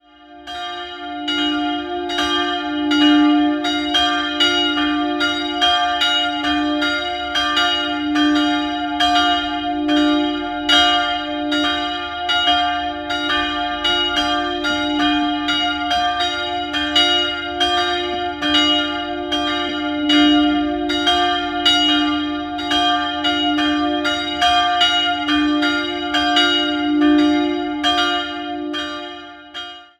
Jahrhundert entstand der heutige Bau, die Ausstattung stammt ebenfalls aus der Barockzeit. 2-stimmiges Geläut: d''-f'' Die Glocken wurden 1951 von Friedrich Wilhelm Schilling in Heidelberg gegossen.